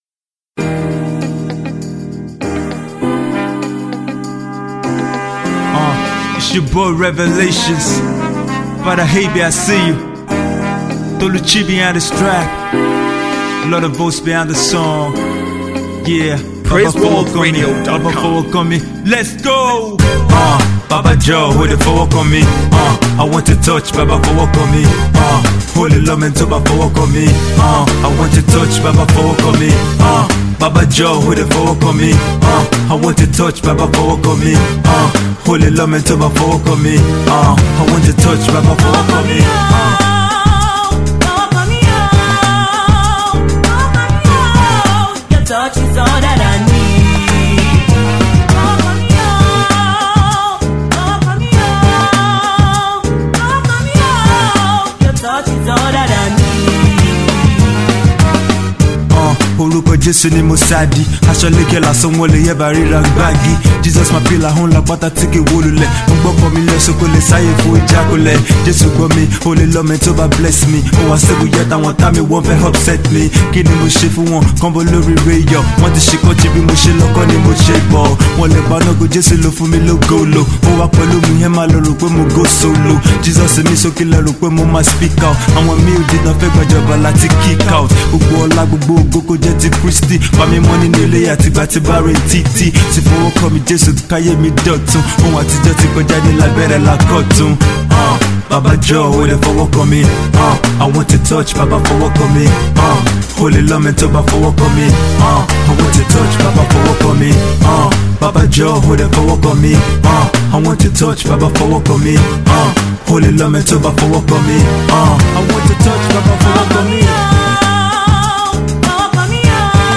gospel Emcee
water tight indigenous lyrical deliveries!